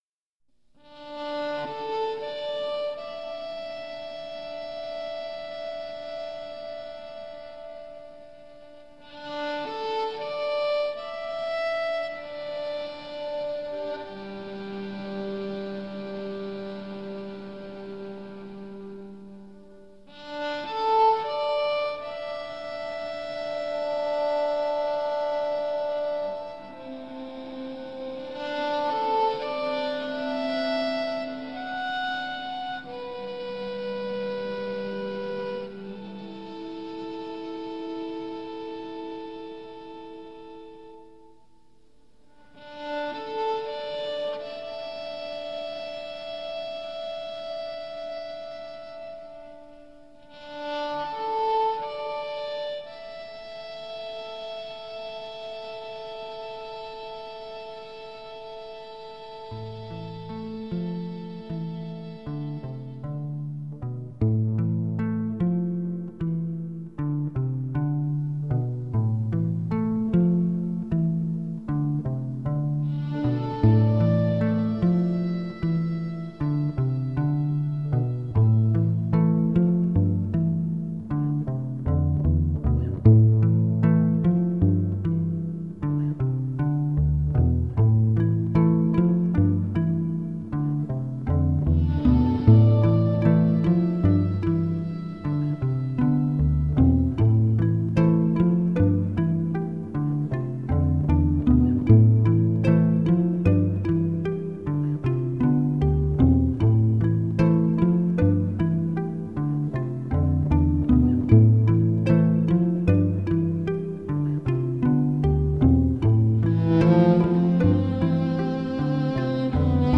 Enchanting cello compositions.
Tagged as: New Age, Ambient, Cello, Ethereal